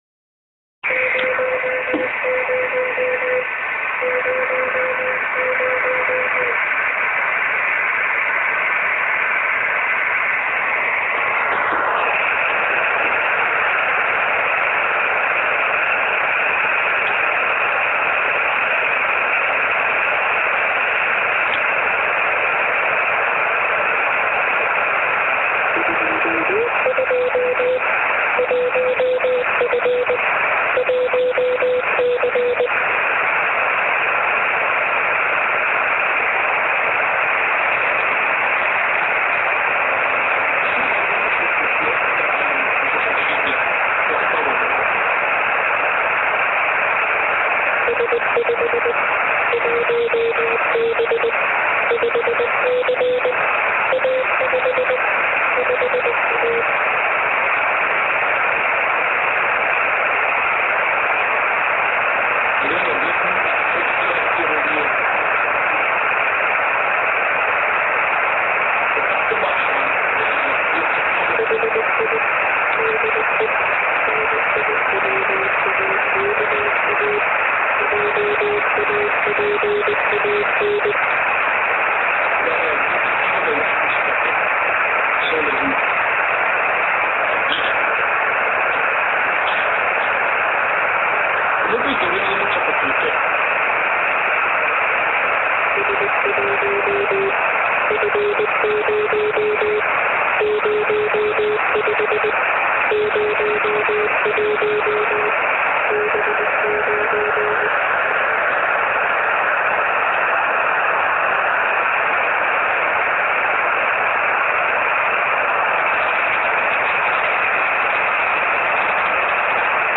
Ho avuto la certezza solo ora di aver sentito il FITSAT-1 perché per compensare l’effetto Doppler il mio collega ha variato la frequenza sul suo glorioso Yaesu FT-817 proprio mentre veniva trasmesso “NIWAKA”; cercando “HIDE JAPAN” su google non avevo trovato riscontri… in realtà era “HI DE”.
a quel punto è scattato il panico: accendi la radio!! eccolo è lui!! come registriamo?? usa il cellulare!!